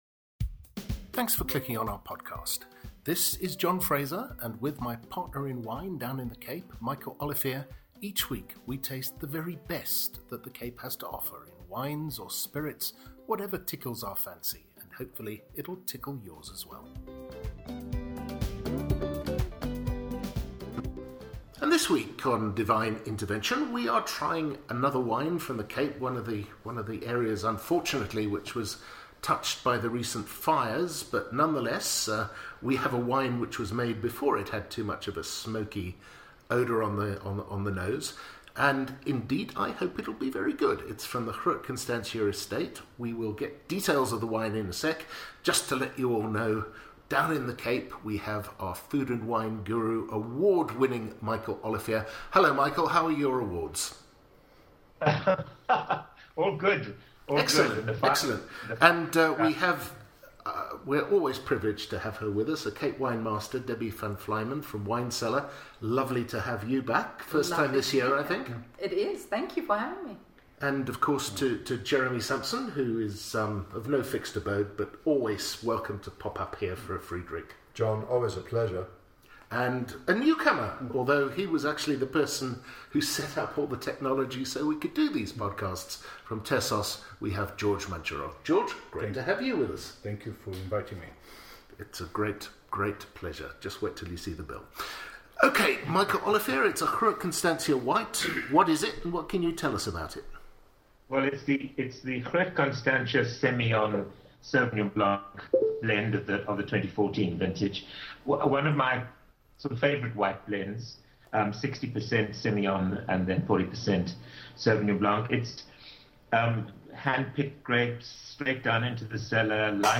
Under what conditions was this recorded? The audio quality on the line from Cape Town was not up to the normal high standard.)